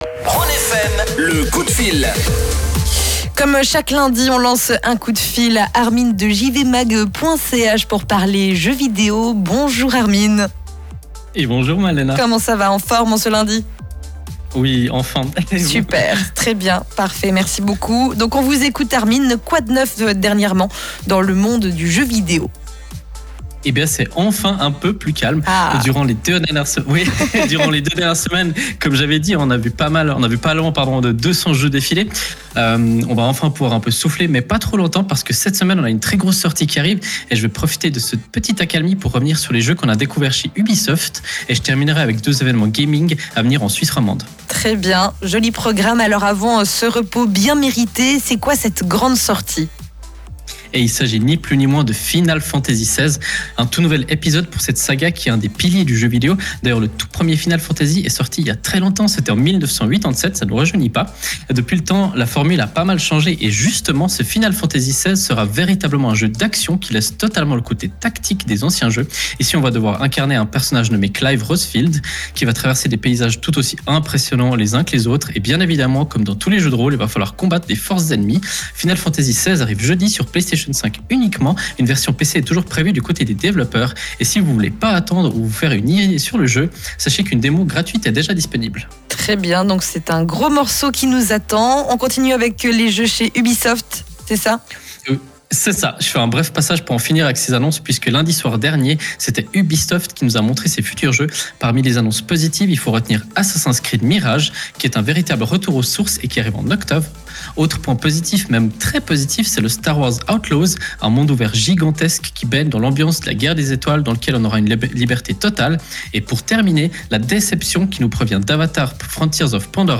Le direct est bien sûr à réécouter juste en dessus.